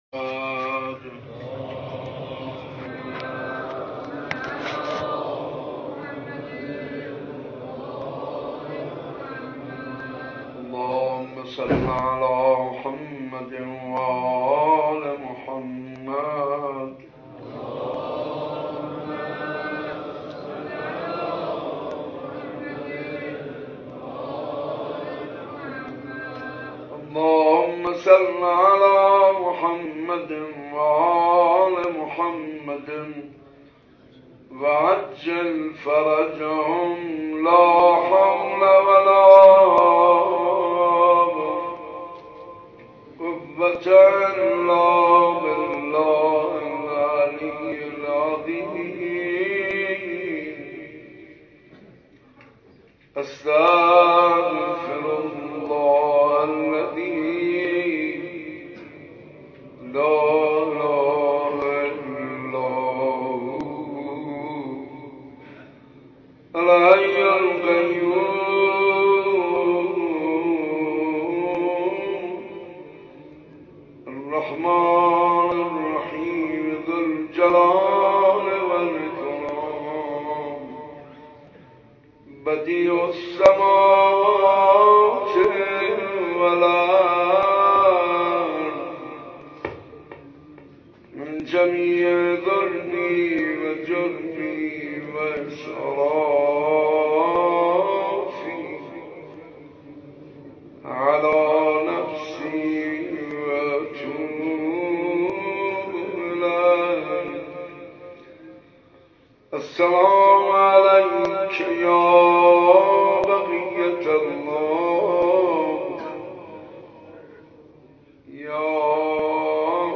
مراسم زیارت عاشورا در صبح نوزدهمین روز محرم در حسینه صنف لباس فروشان تهران برگزار شد.